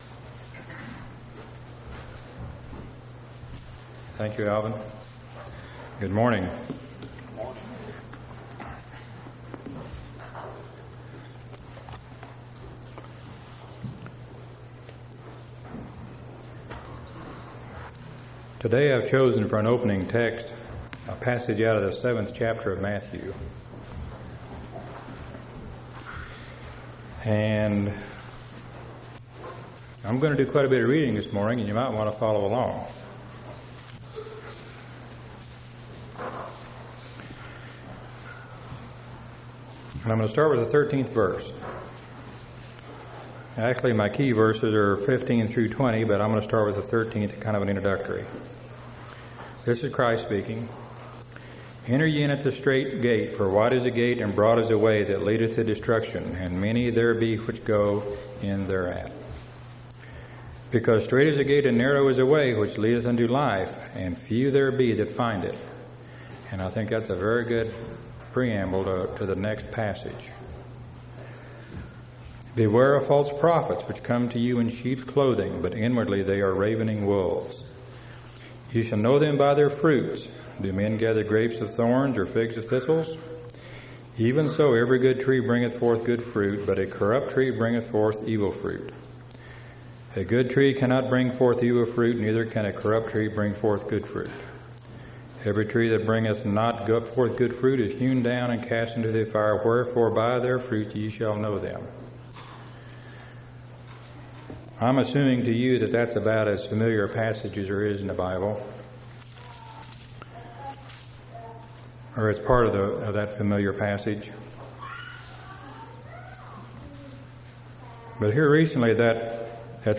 4/9/2000 Location: Temple Lot Local Event